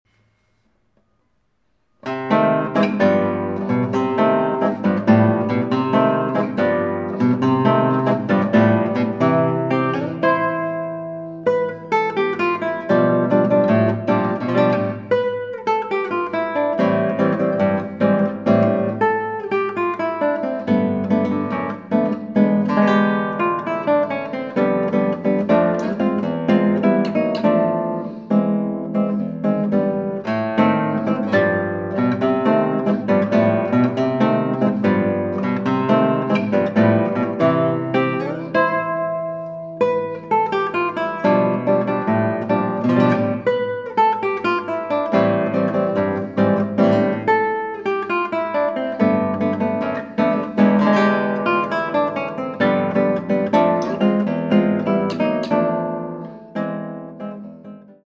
Musik für Gitarre